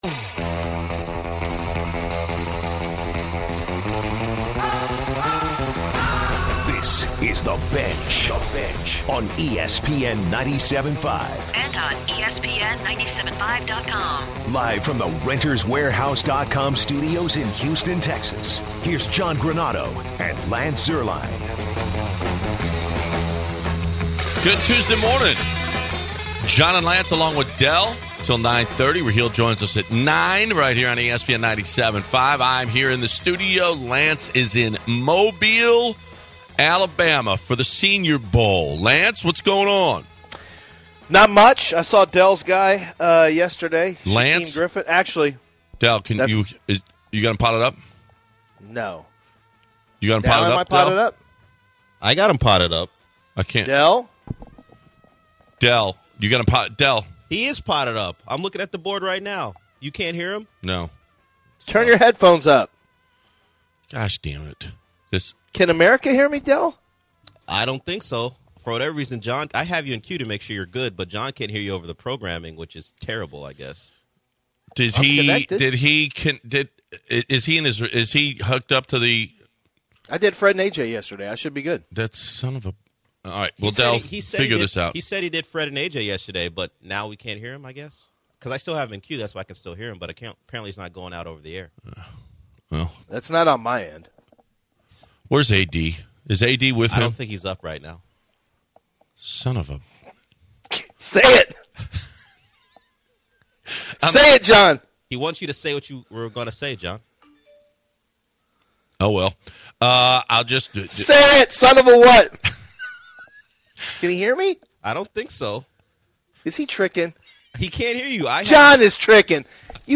live from Mobile for the Senior Bowl